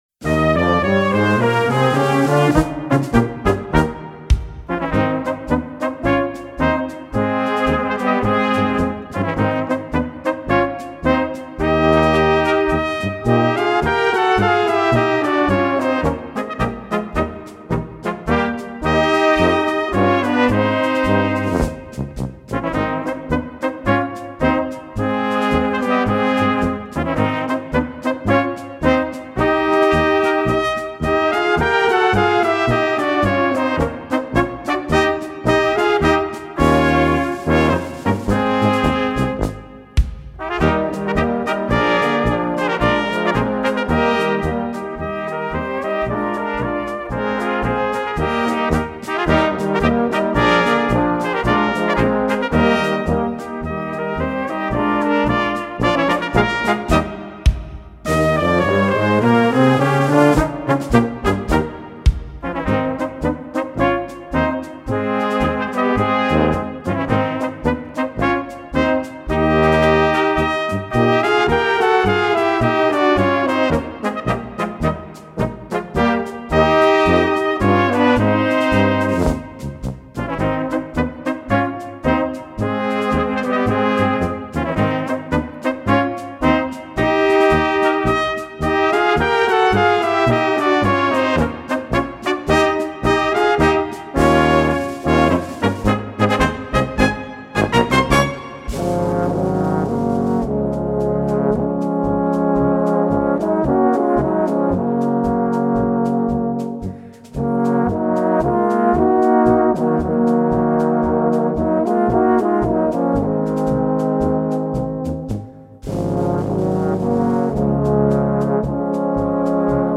Gattung: Polka
Besetzung: Kleine Blasmusik-Besetzung